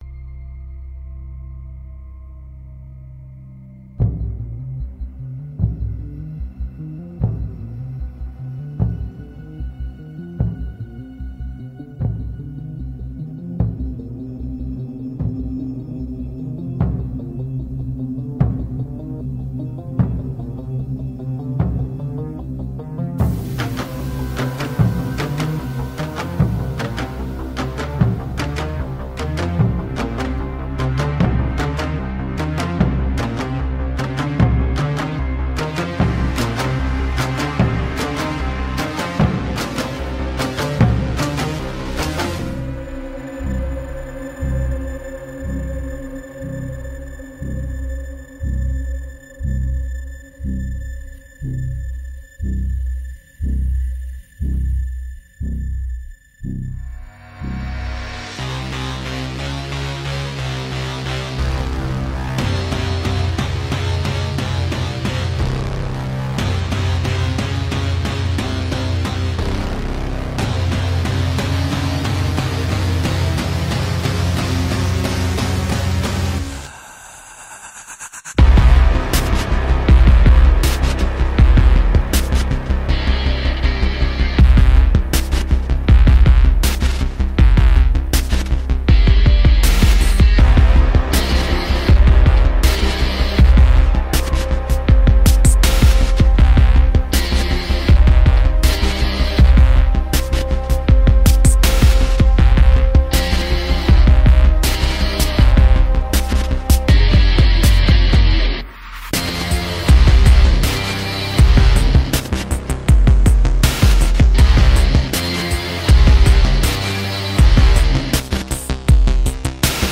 اهنگ‌متن